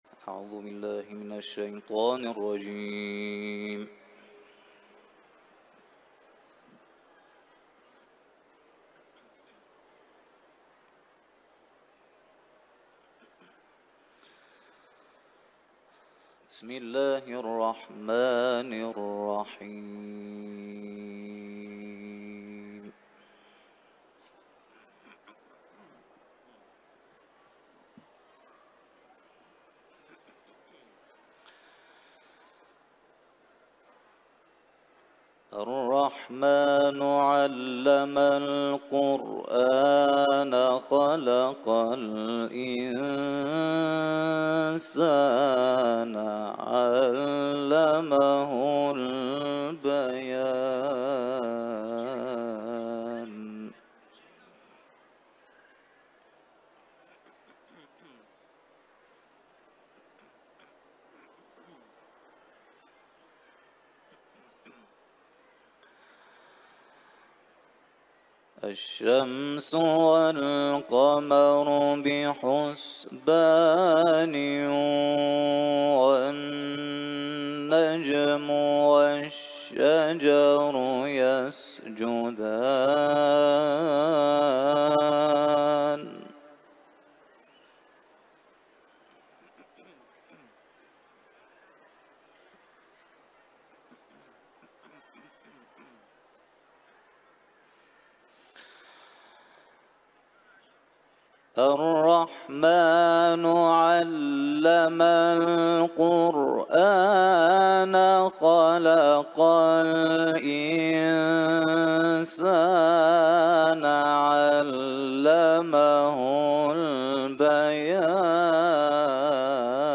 Rahman suresini tilaveti